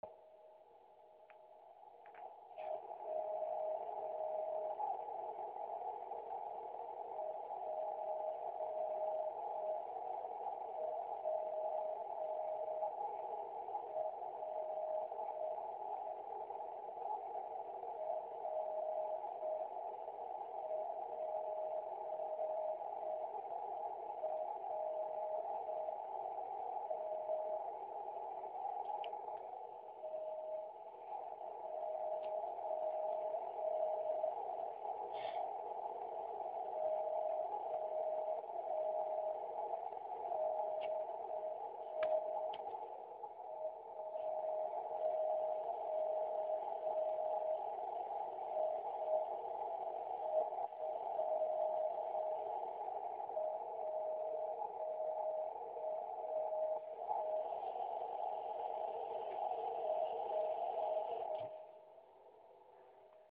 Tedy typické šíření povrchovou vlnou.
Žádná veliká síla to není, ale je to slyšet.
Rychlost vysílání 5WPM